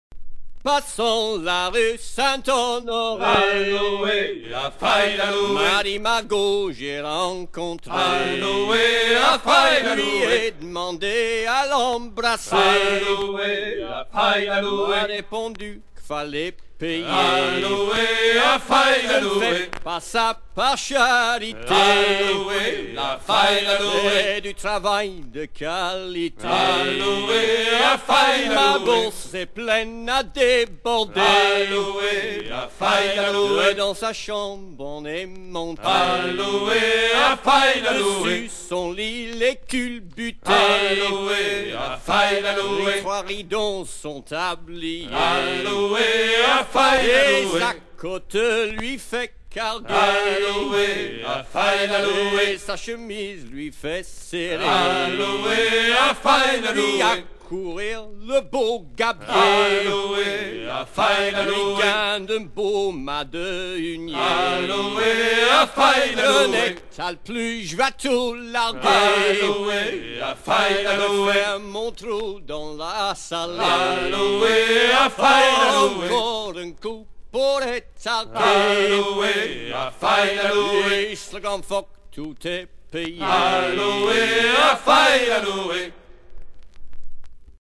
gestuel : à hisser à grands coups
Genre laisse
Pièce musicale éditée